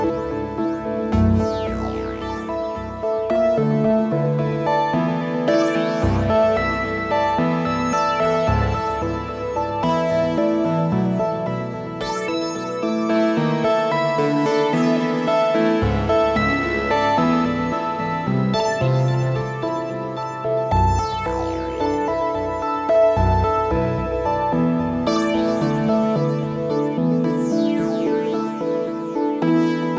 muzyka elektroniczna, ambient